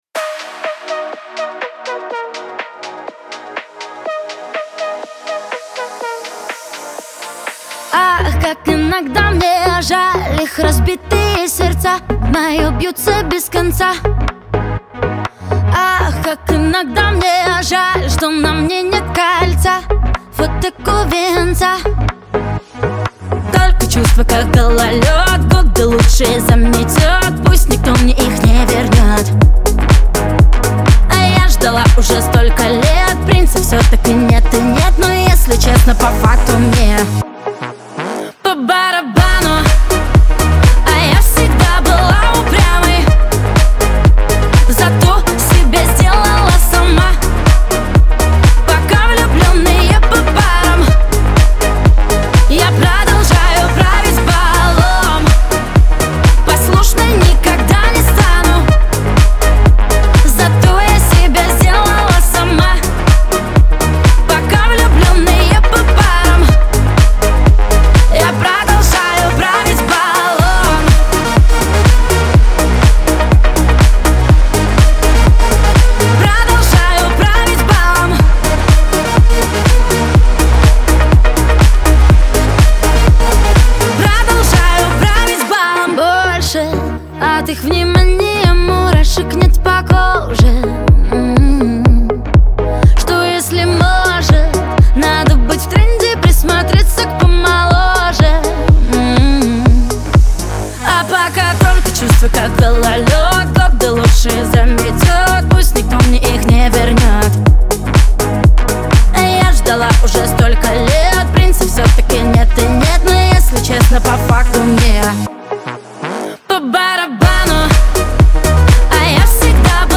эстрада , диско
pop , dance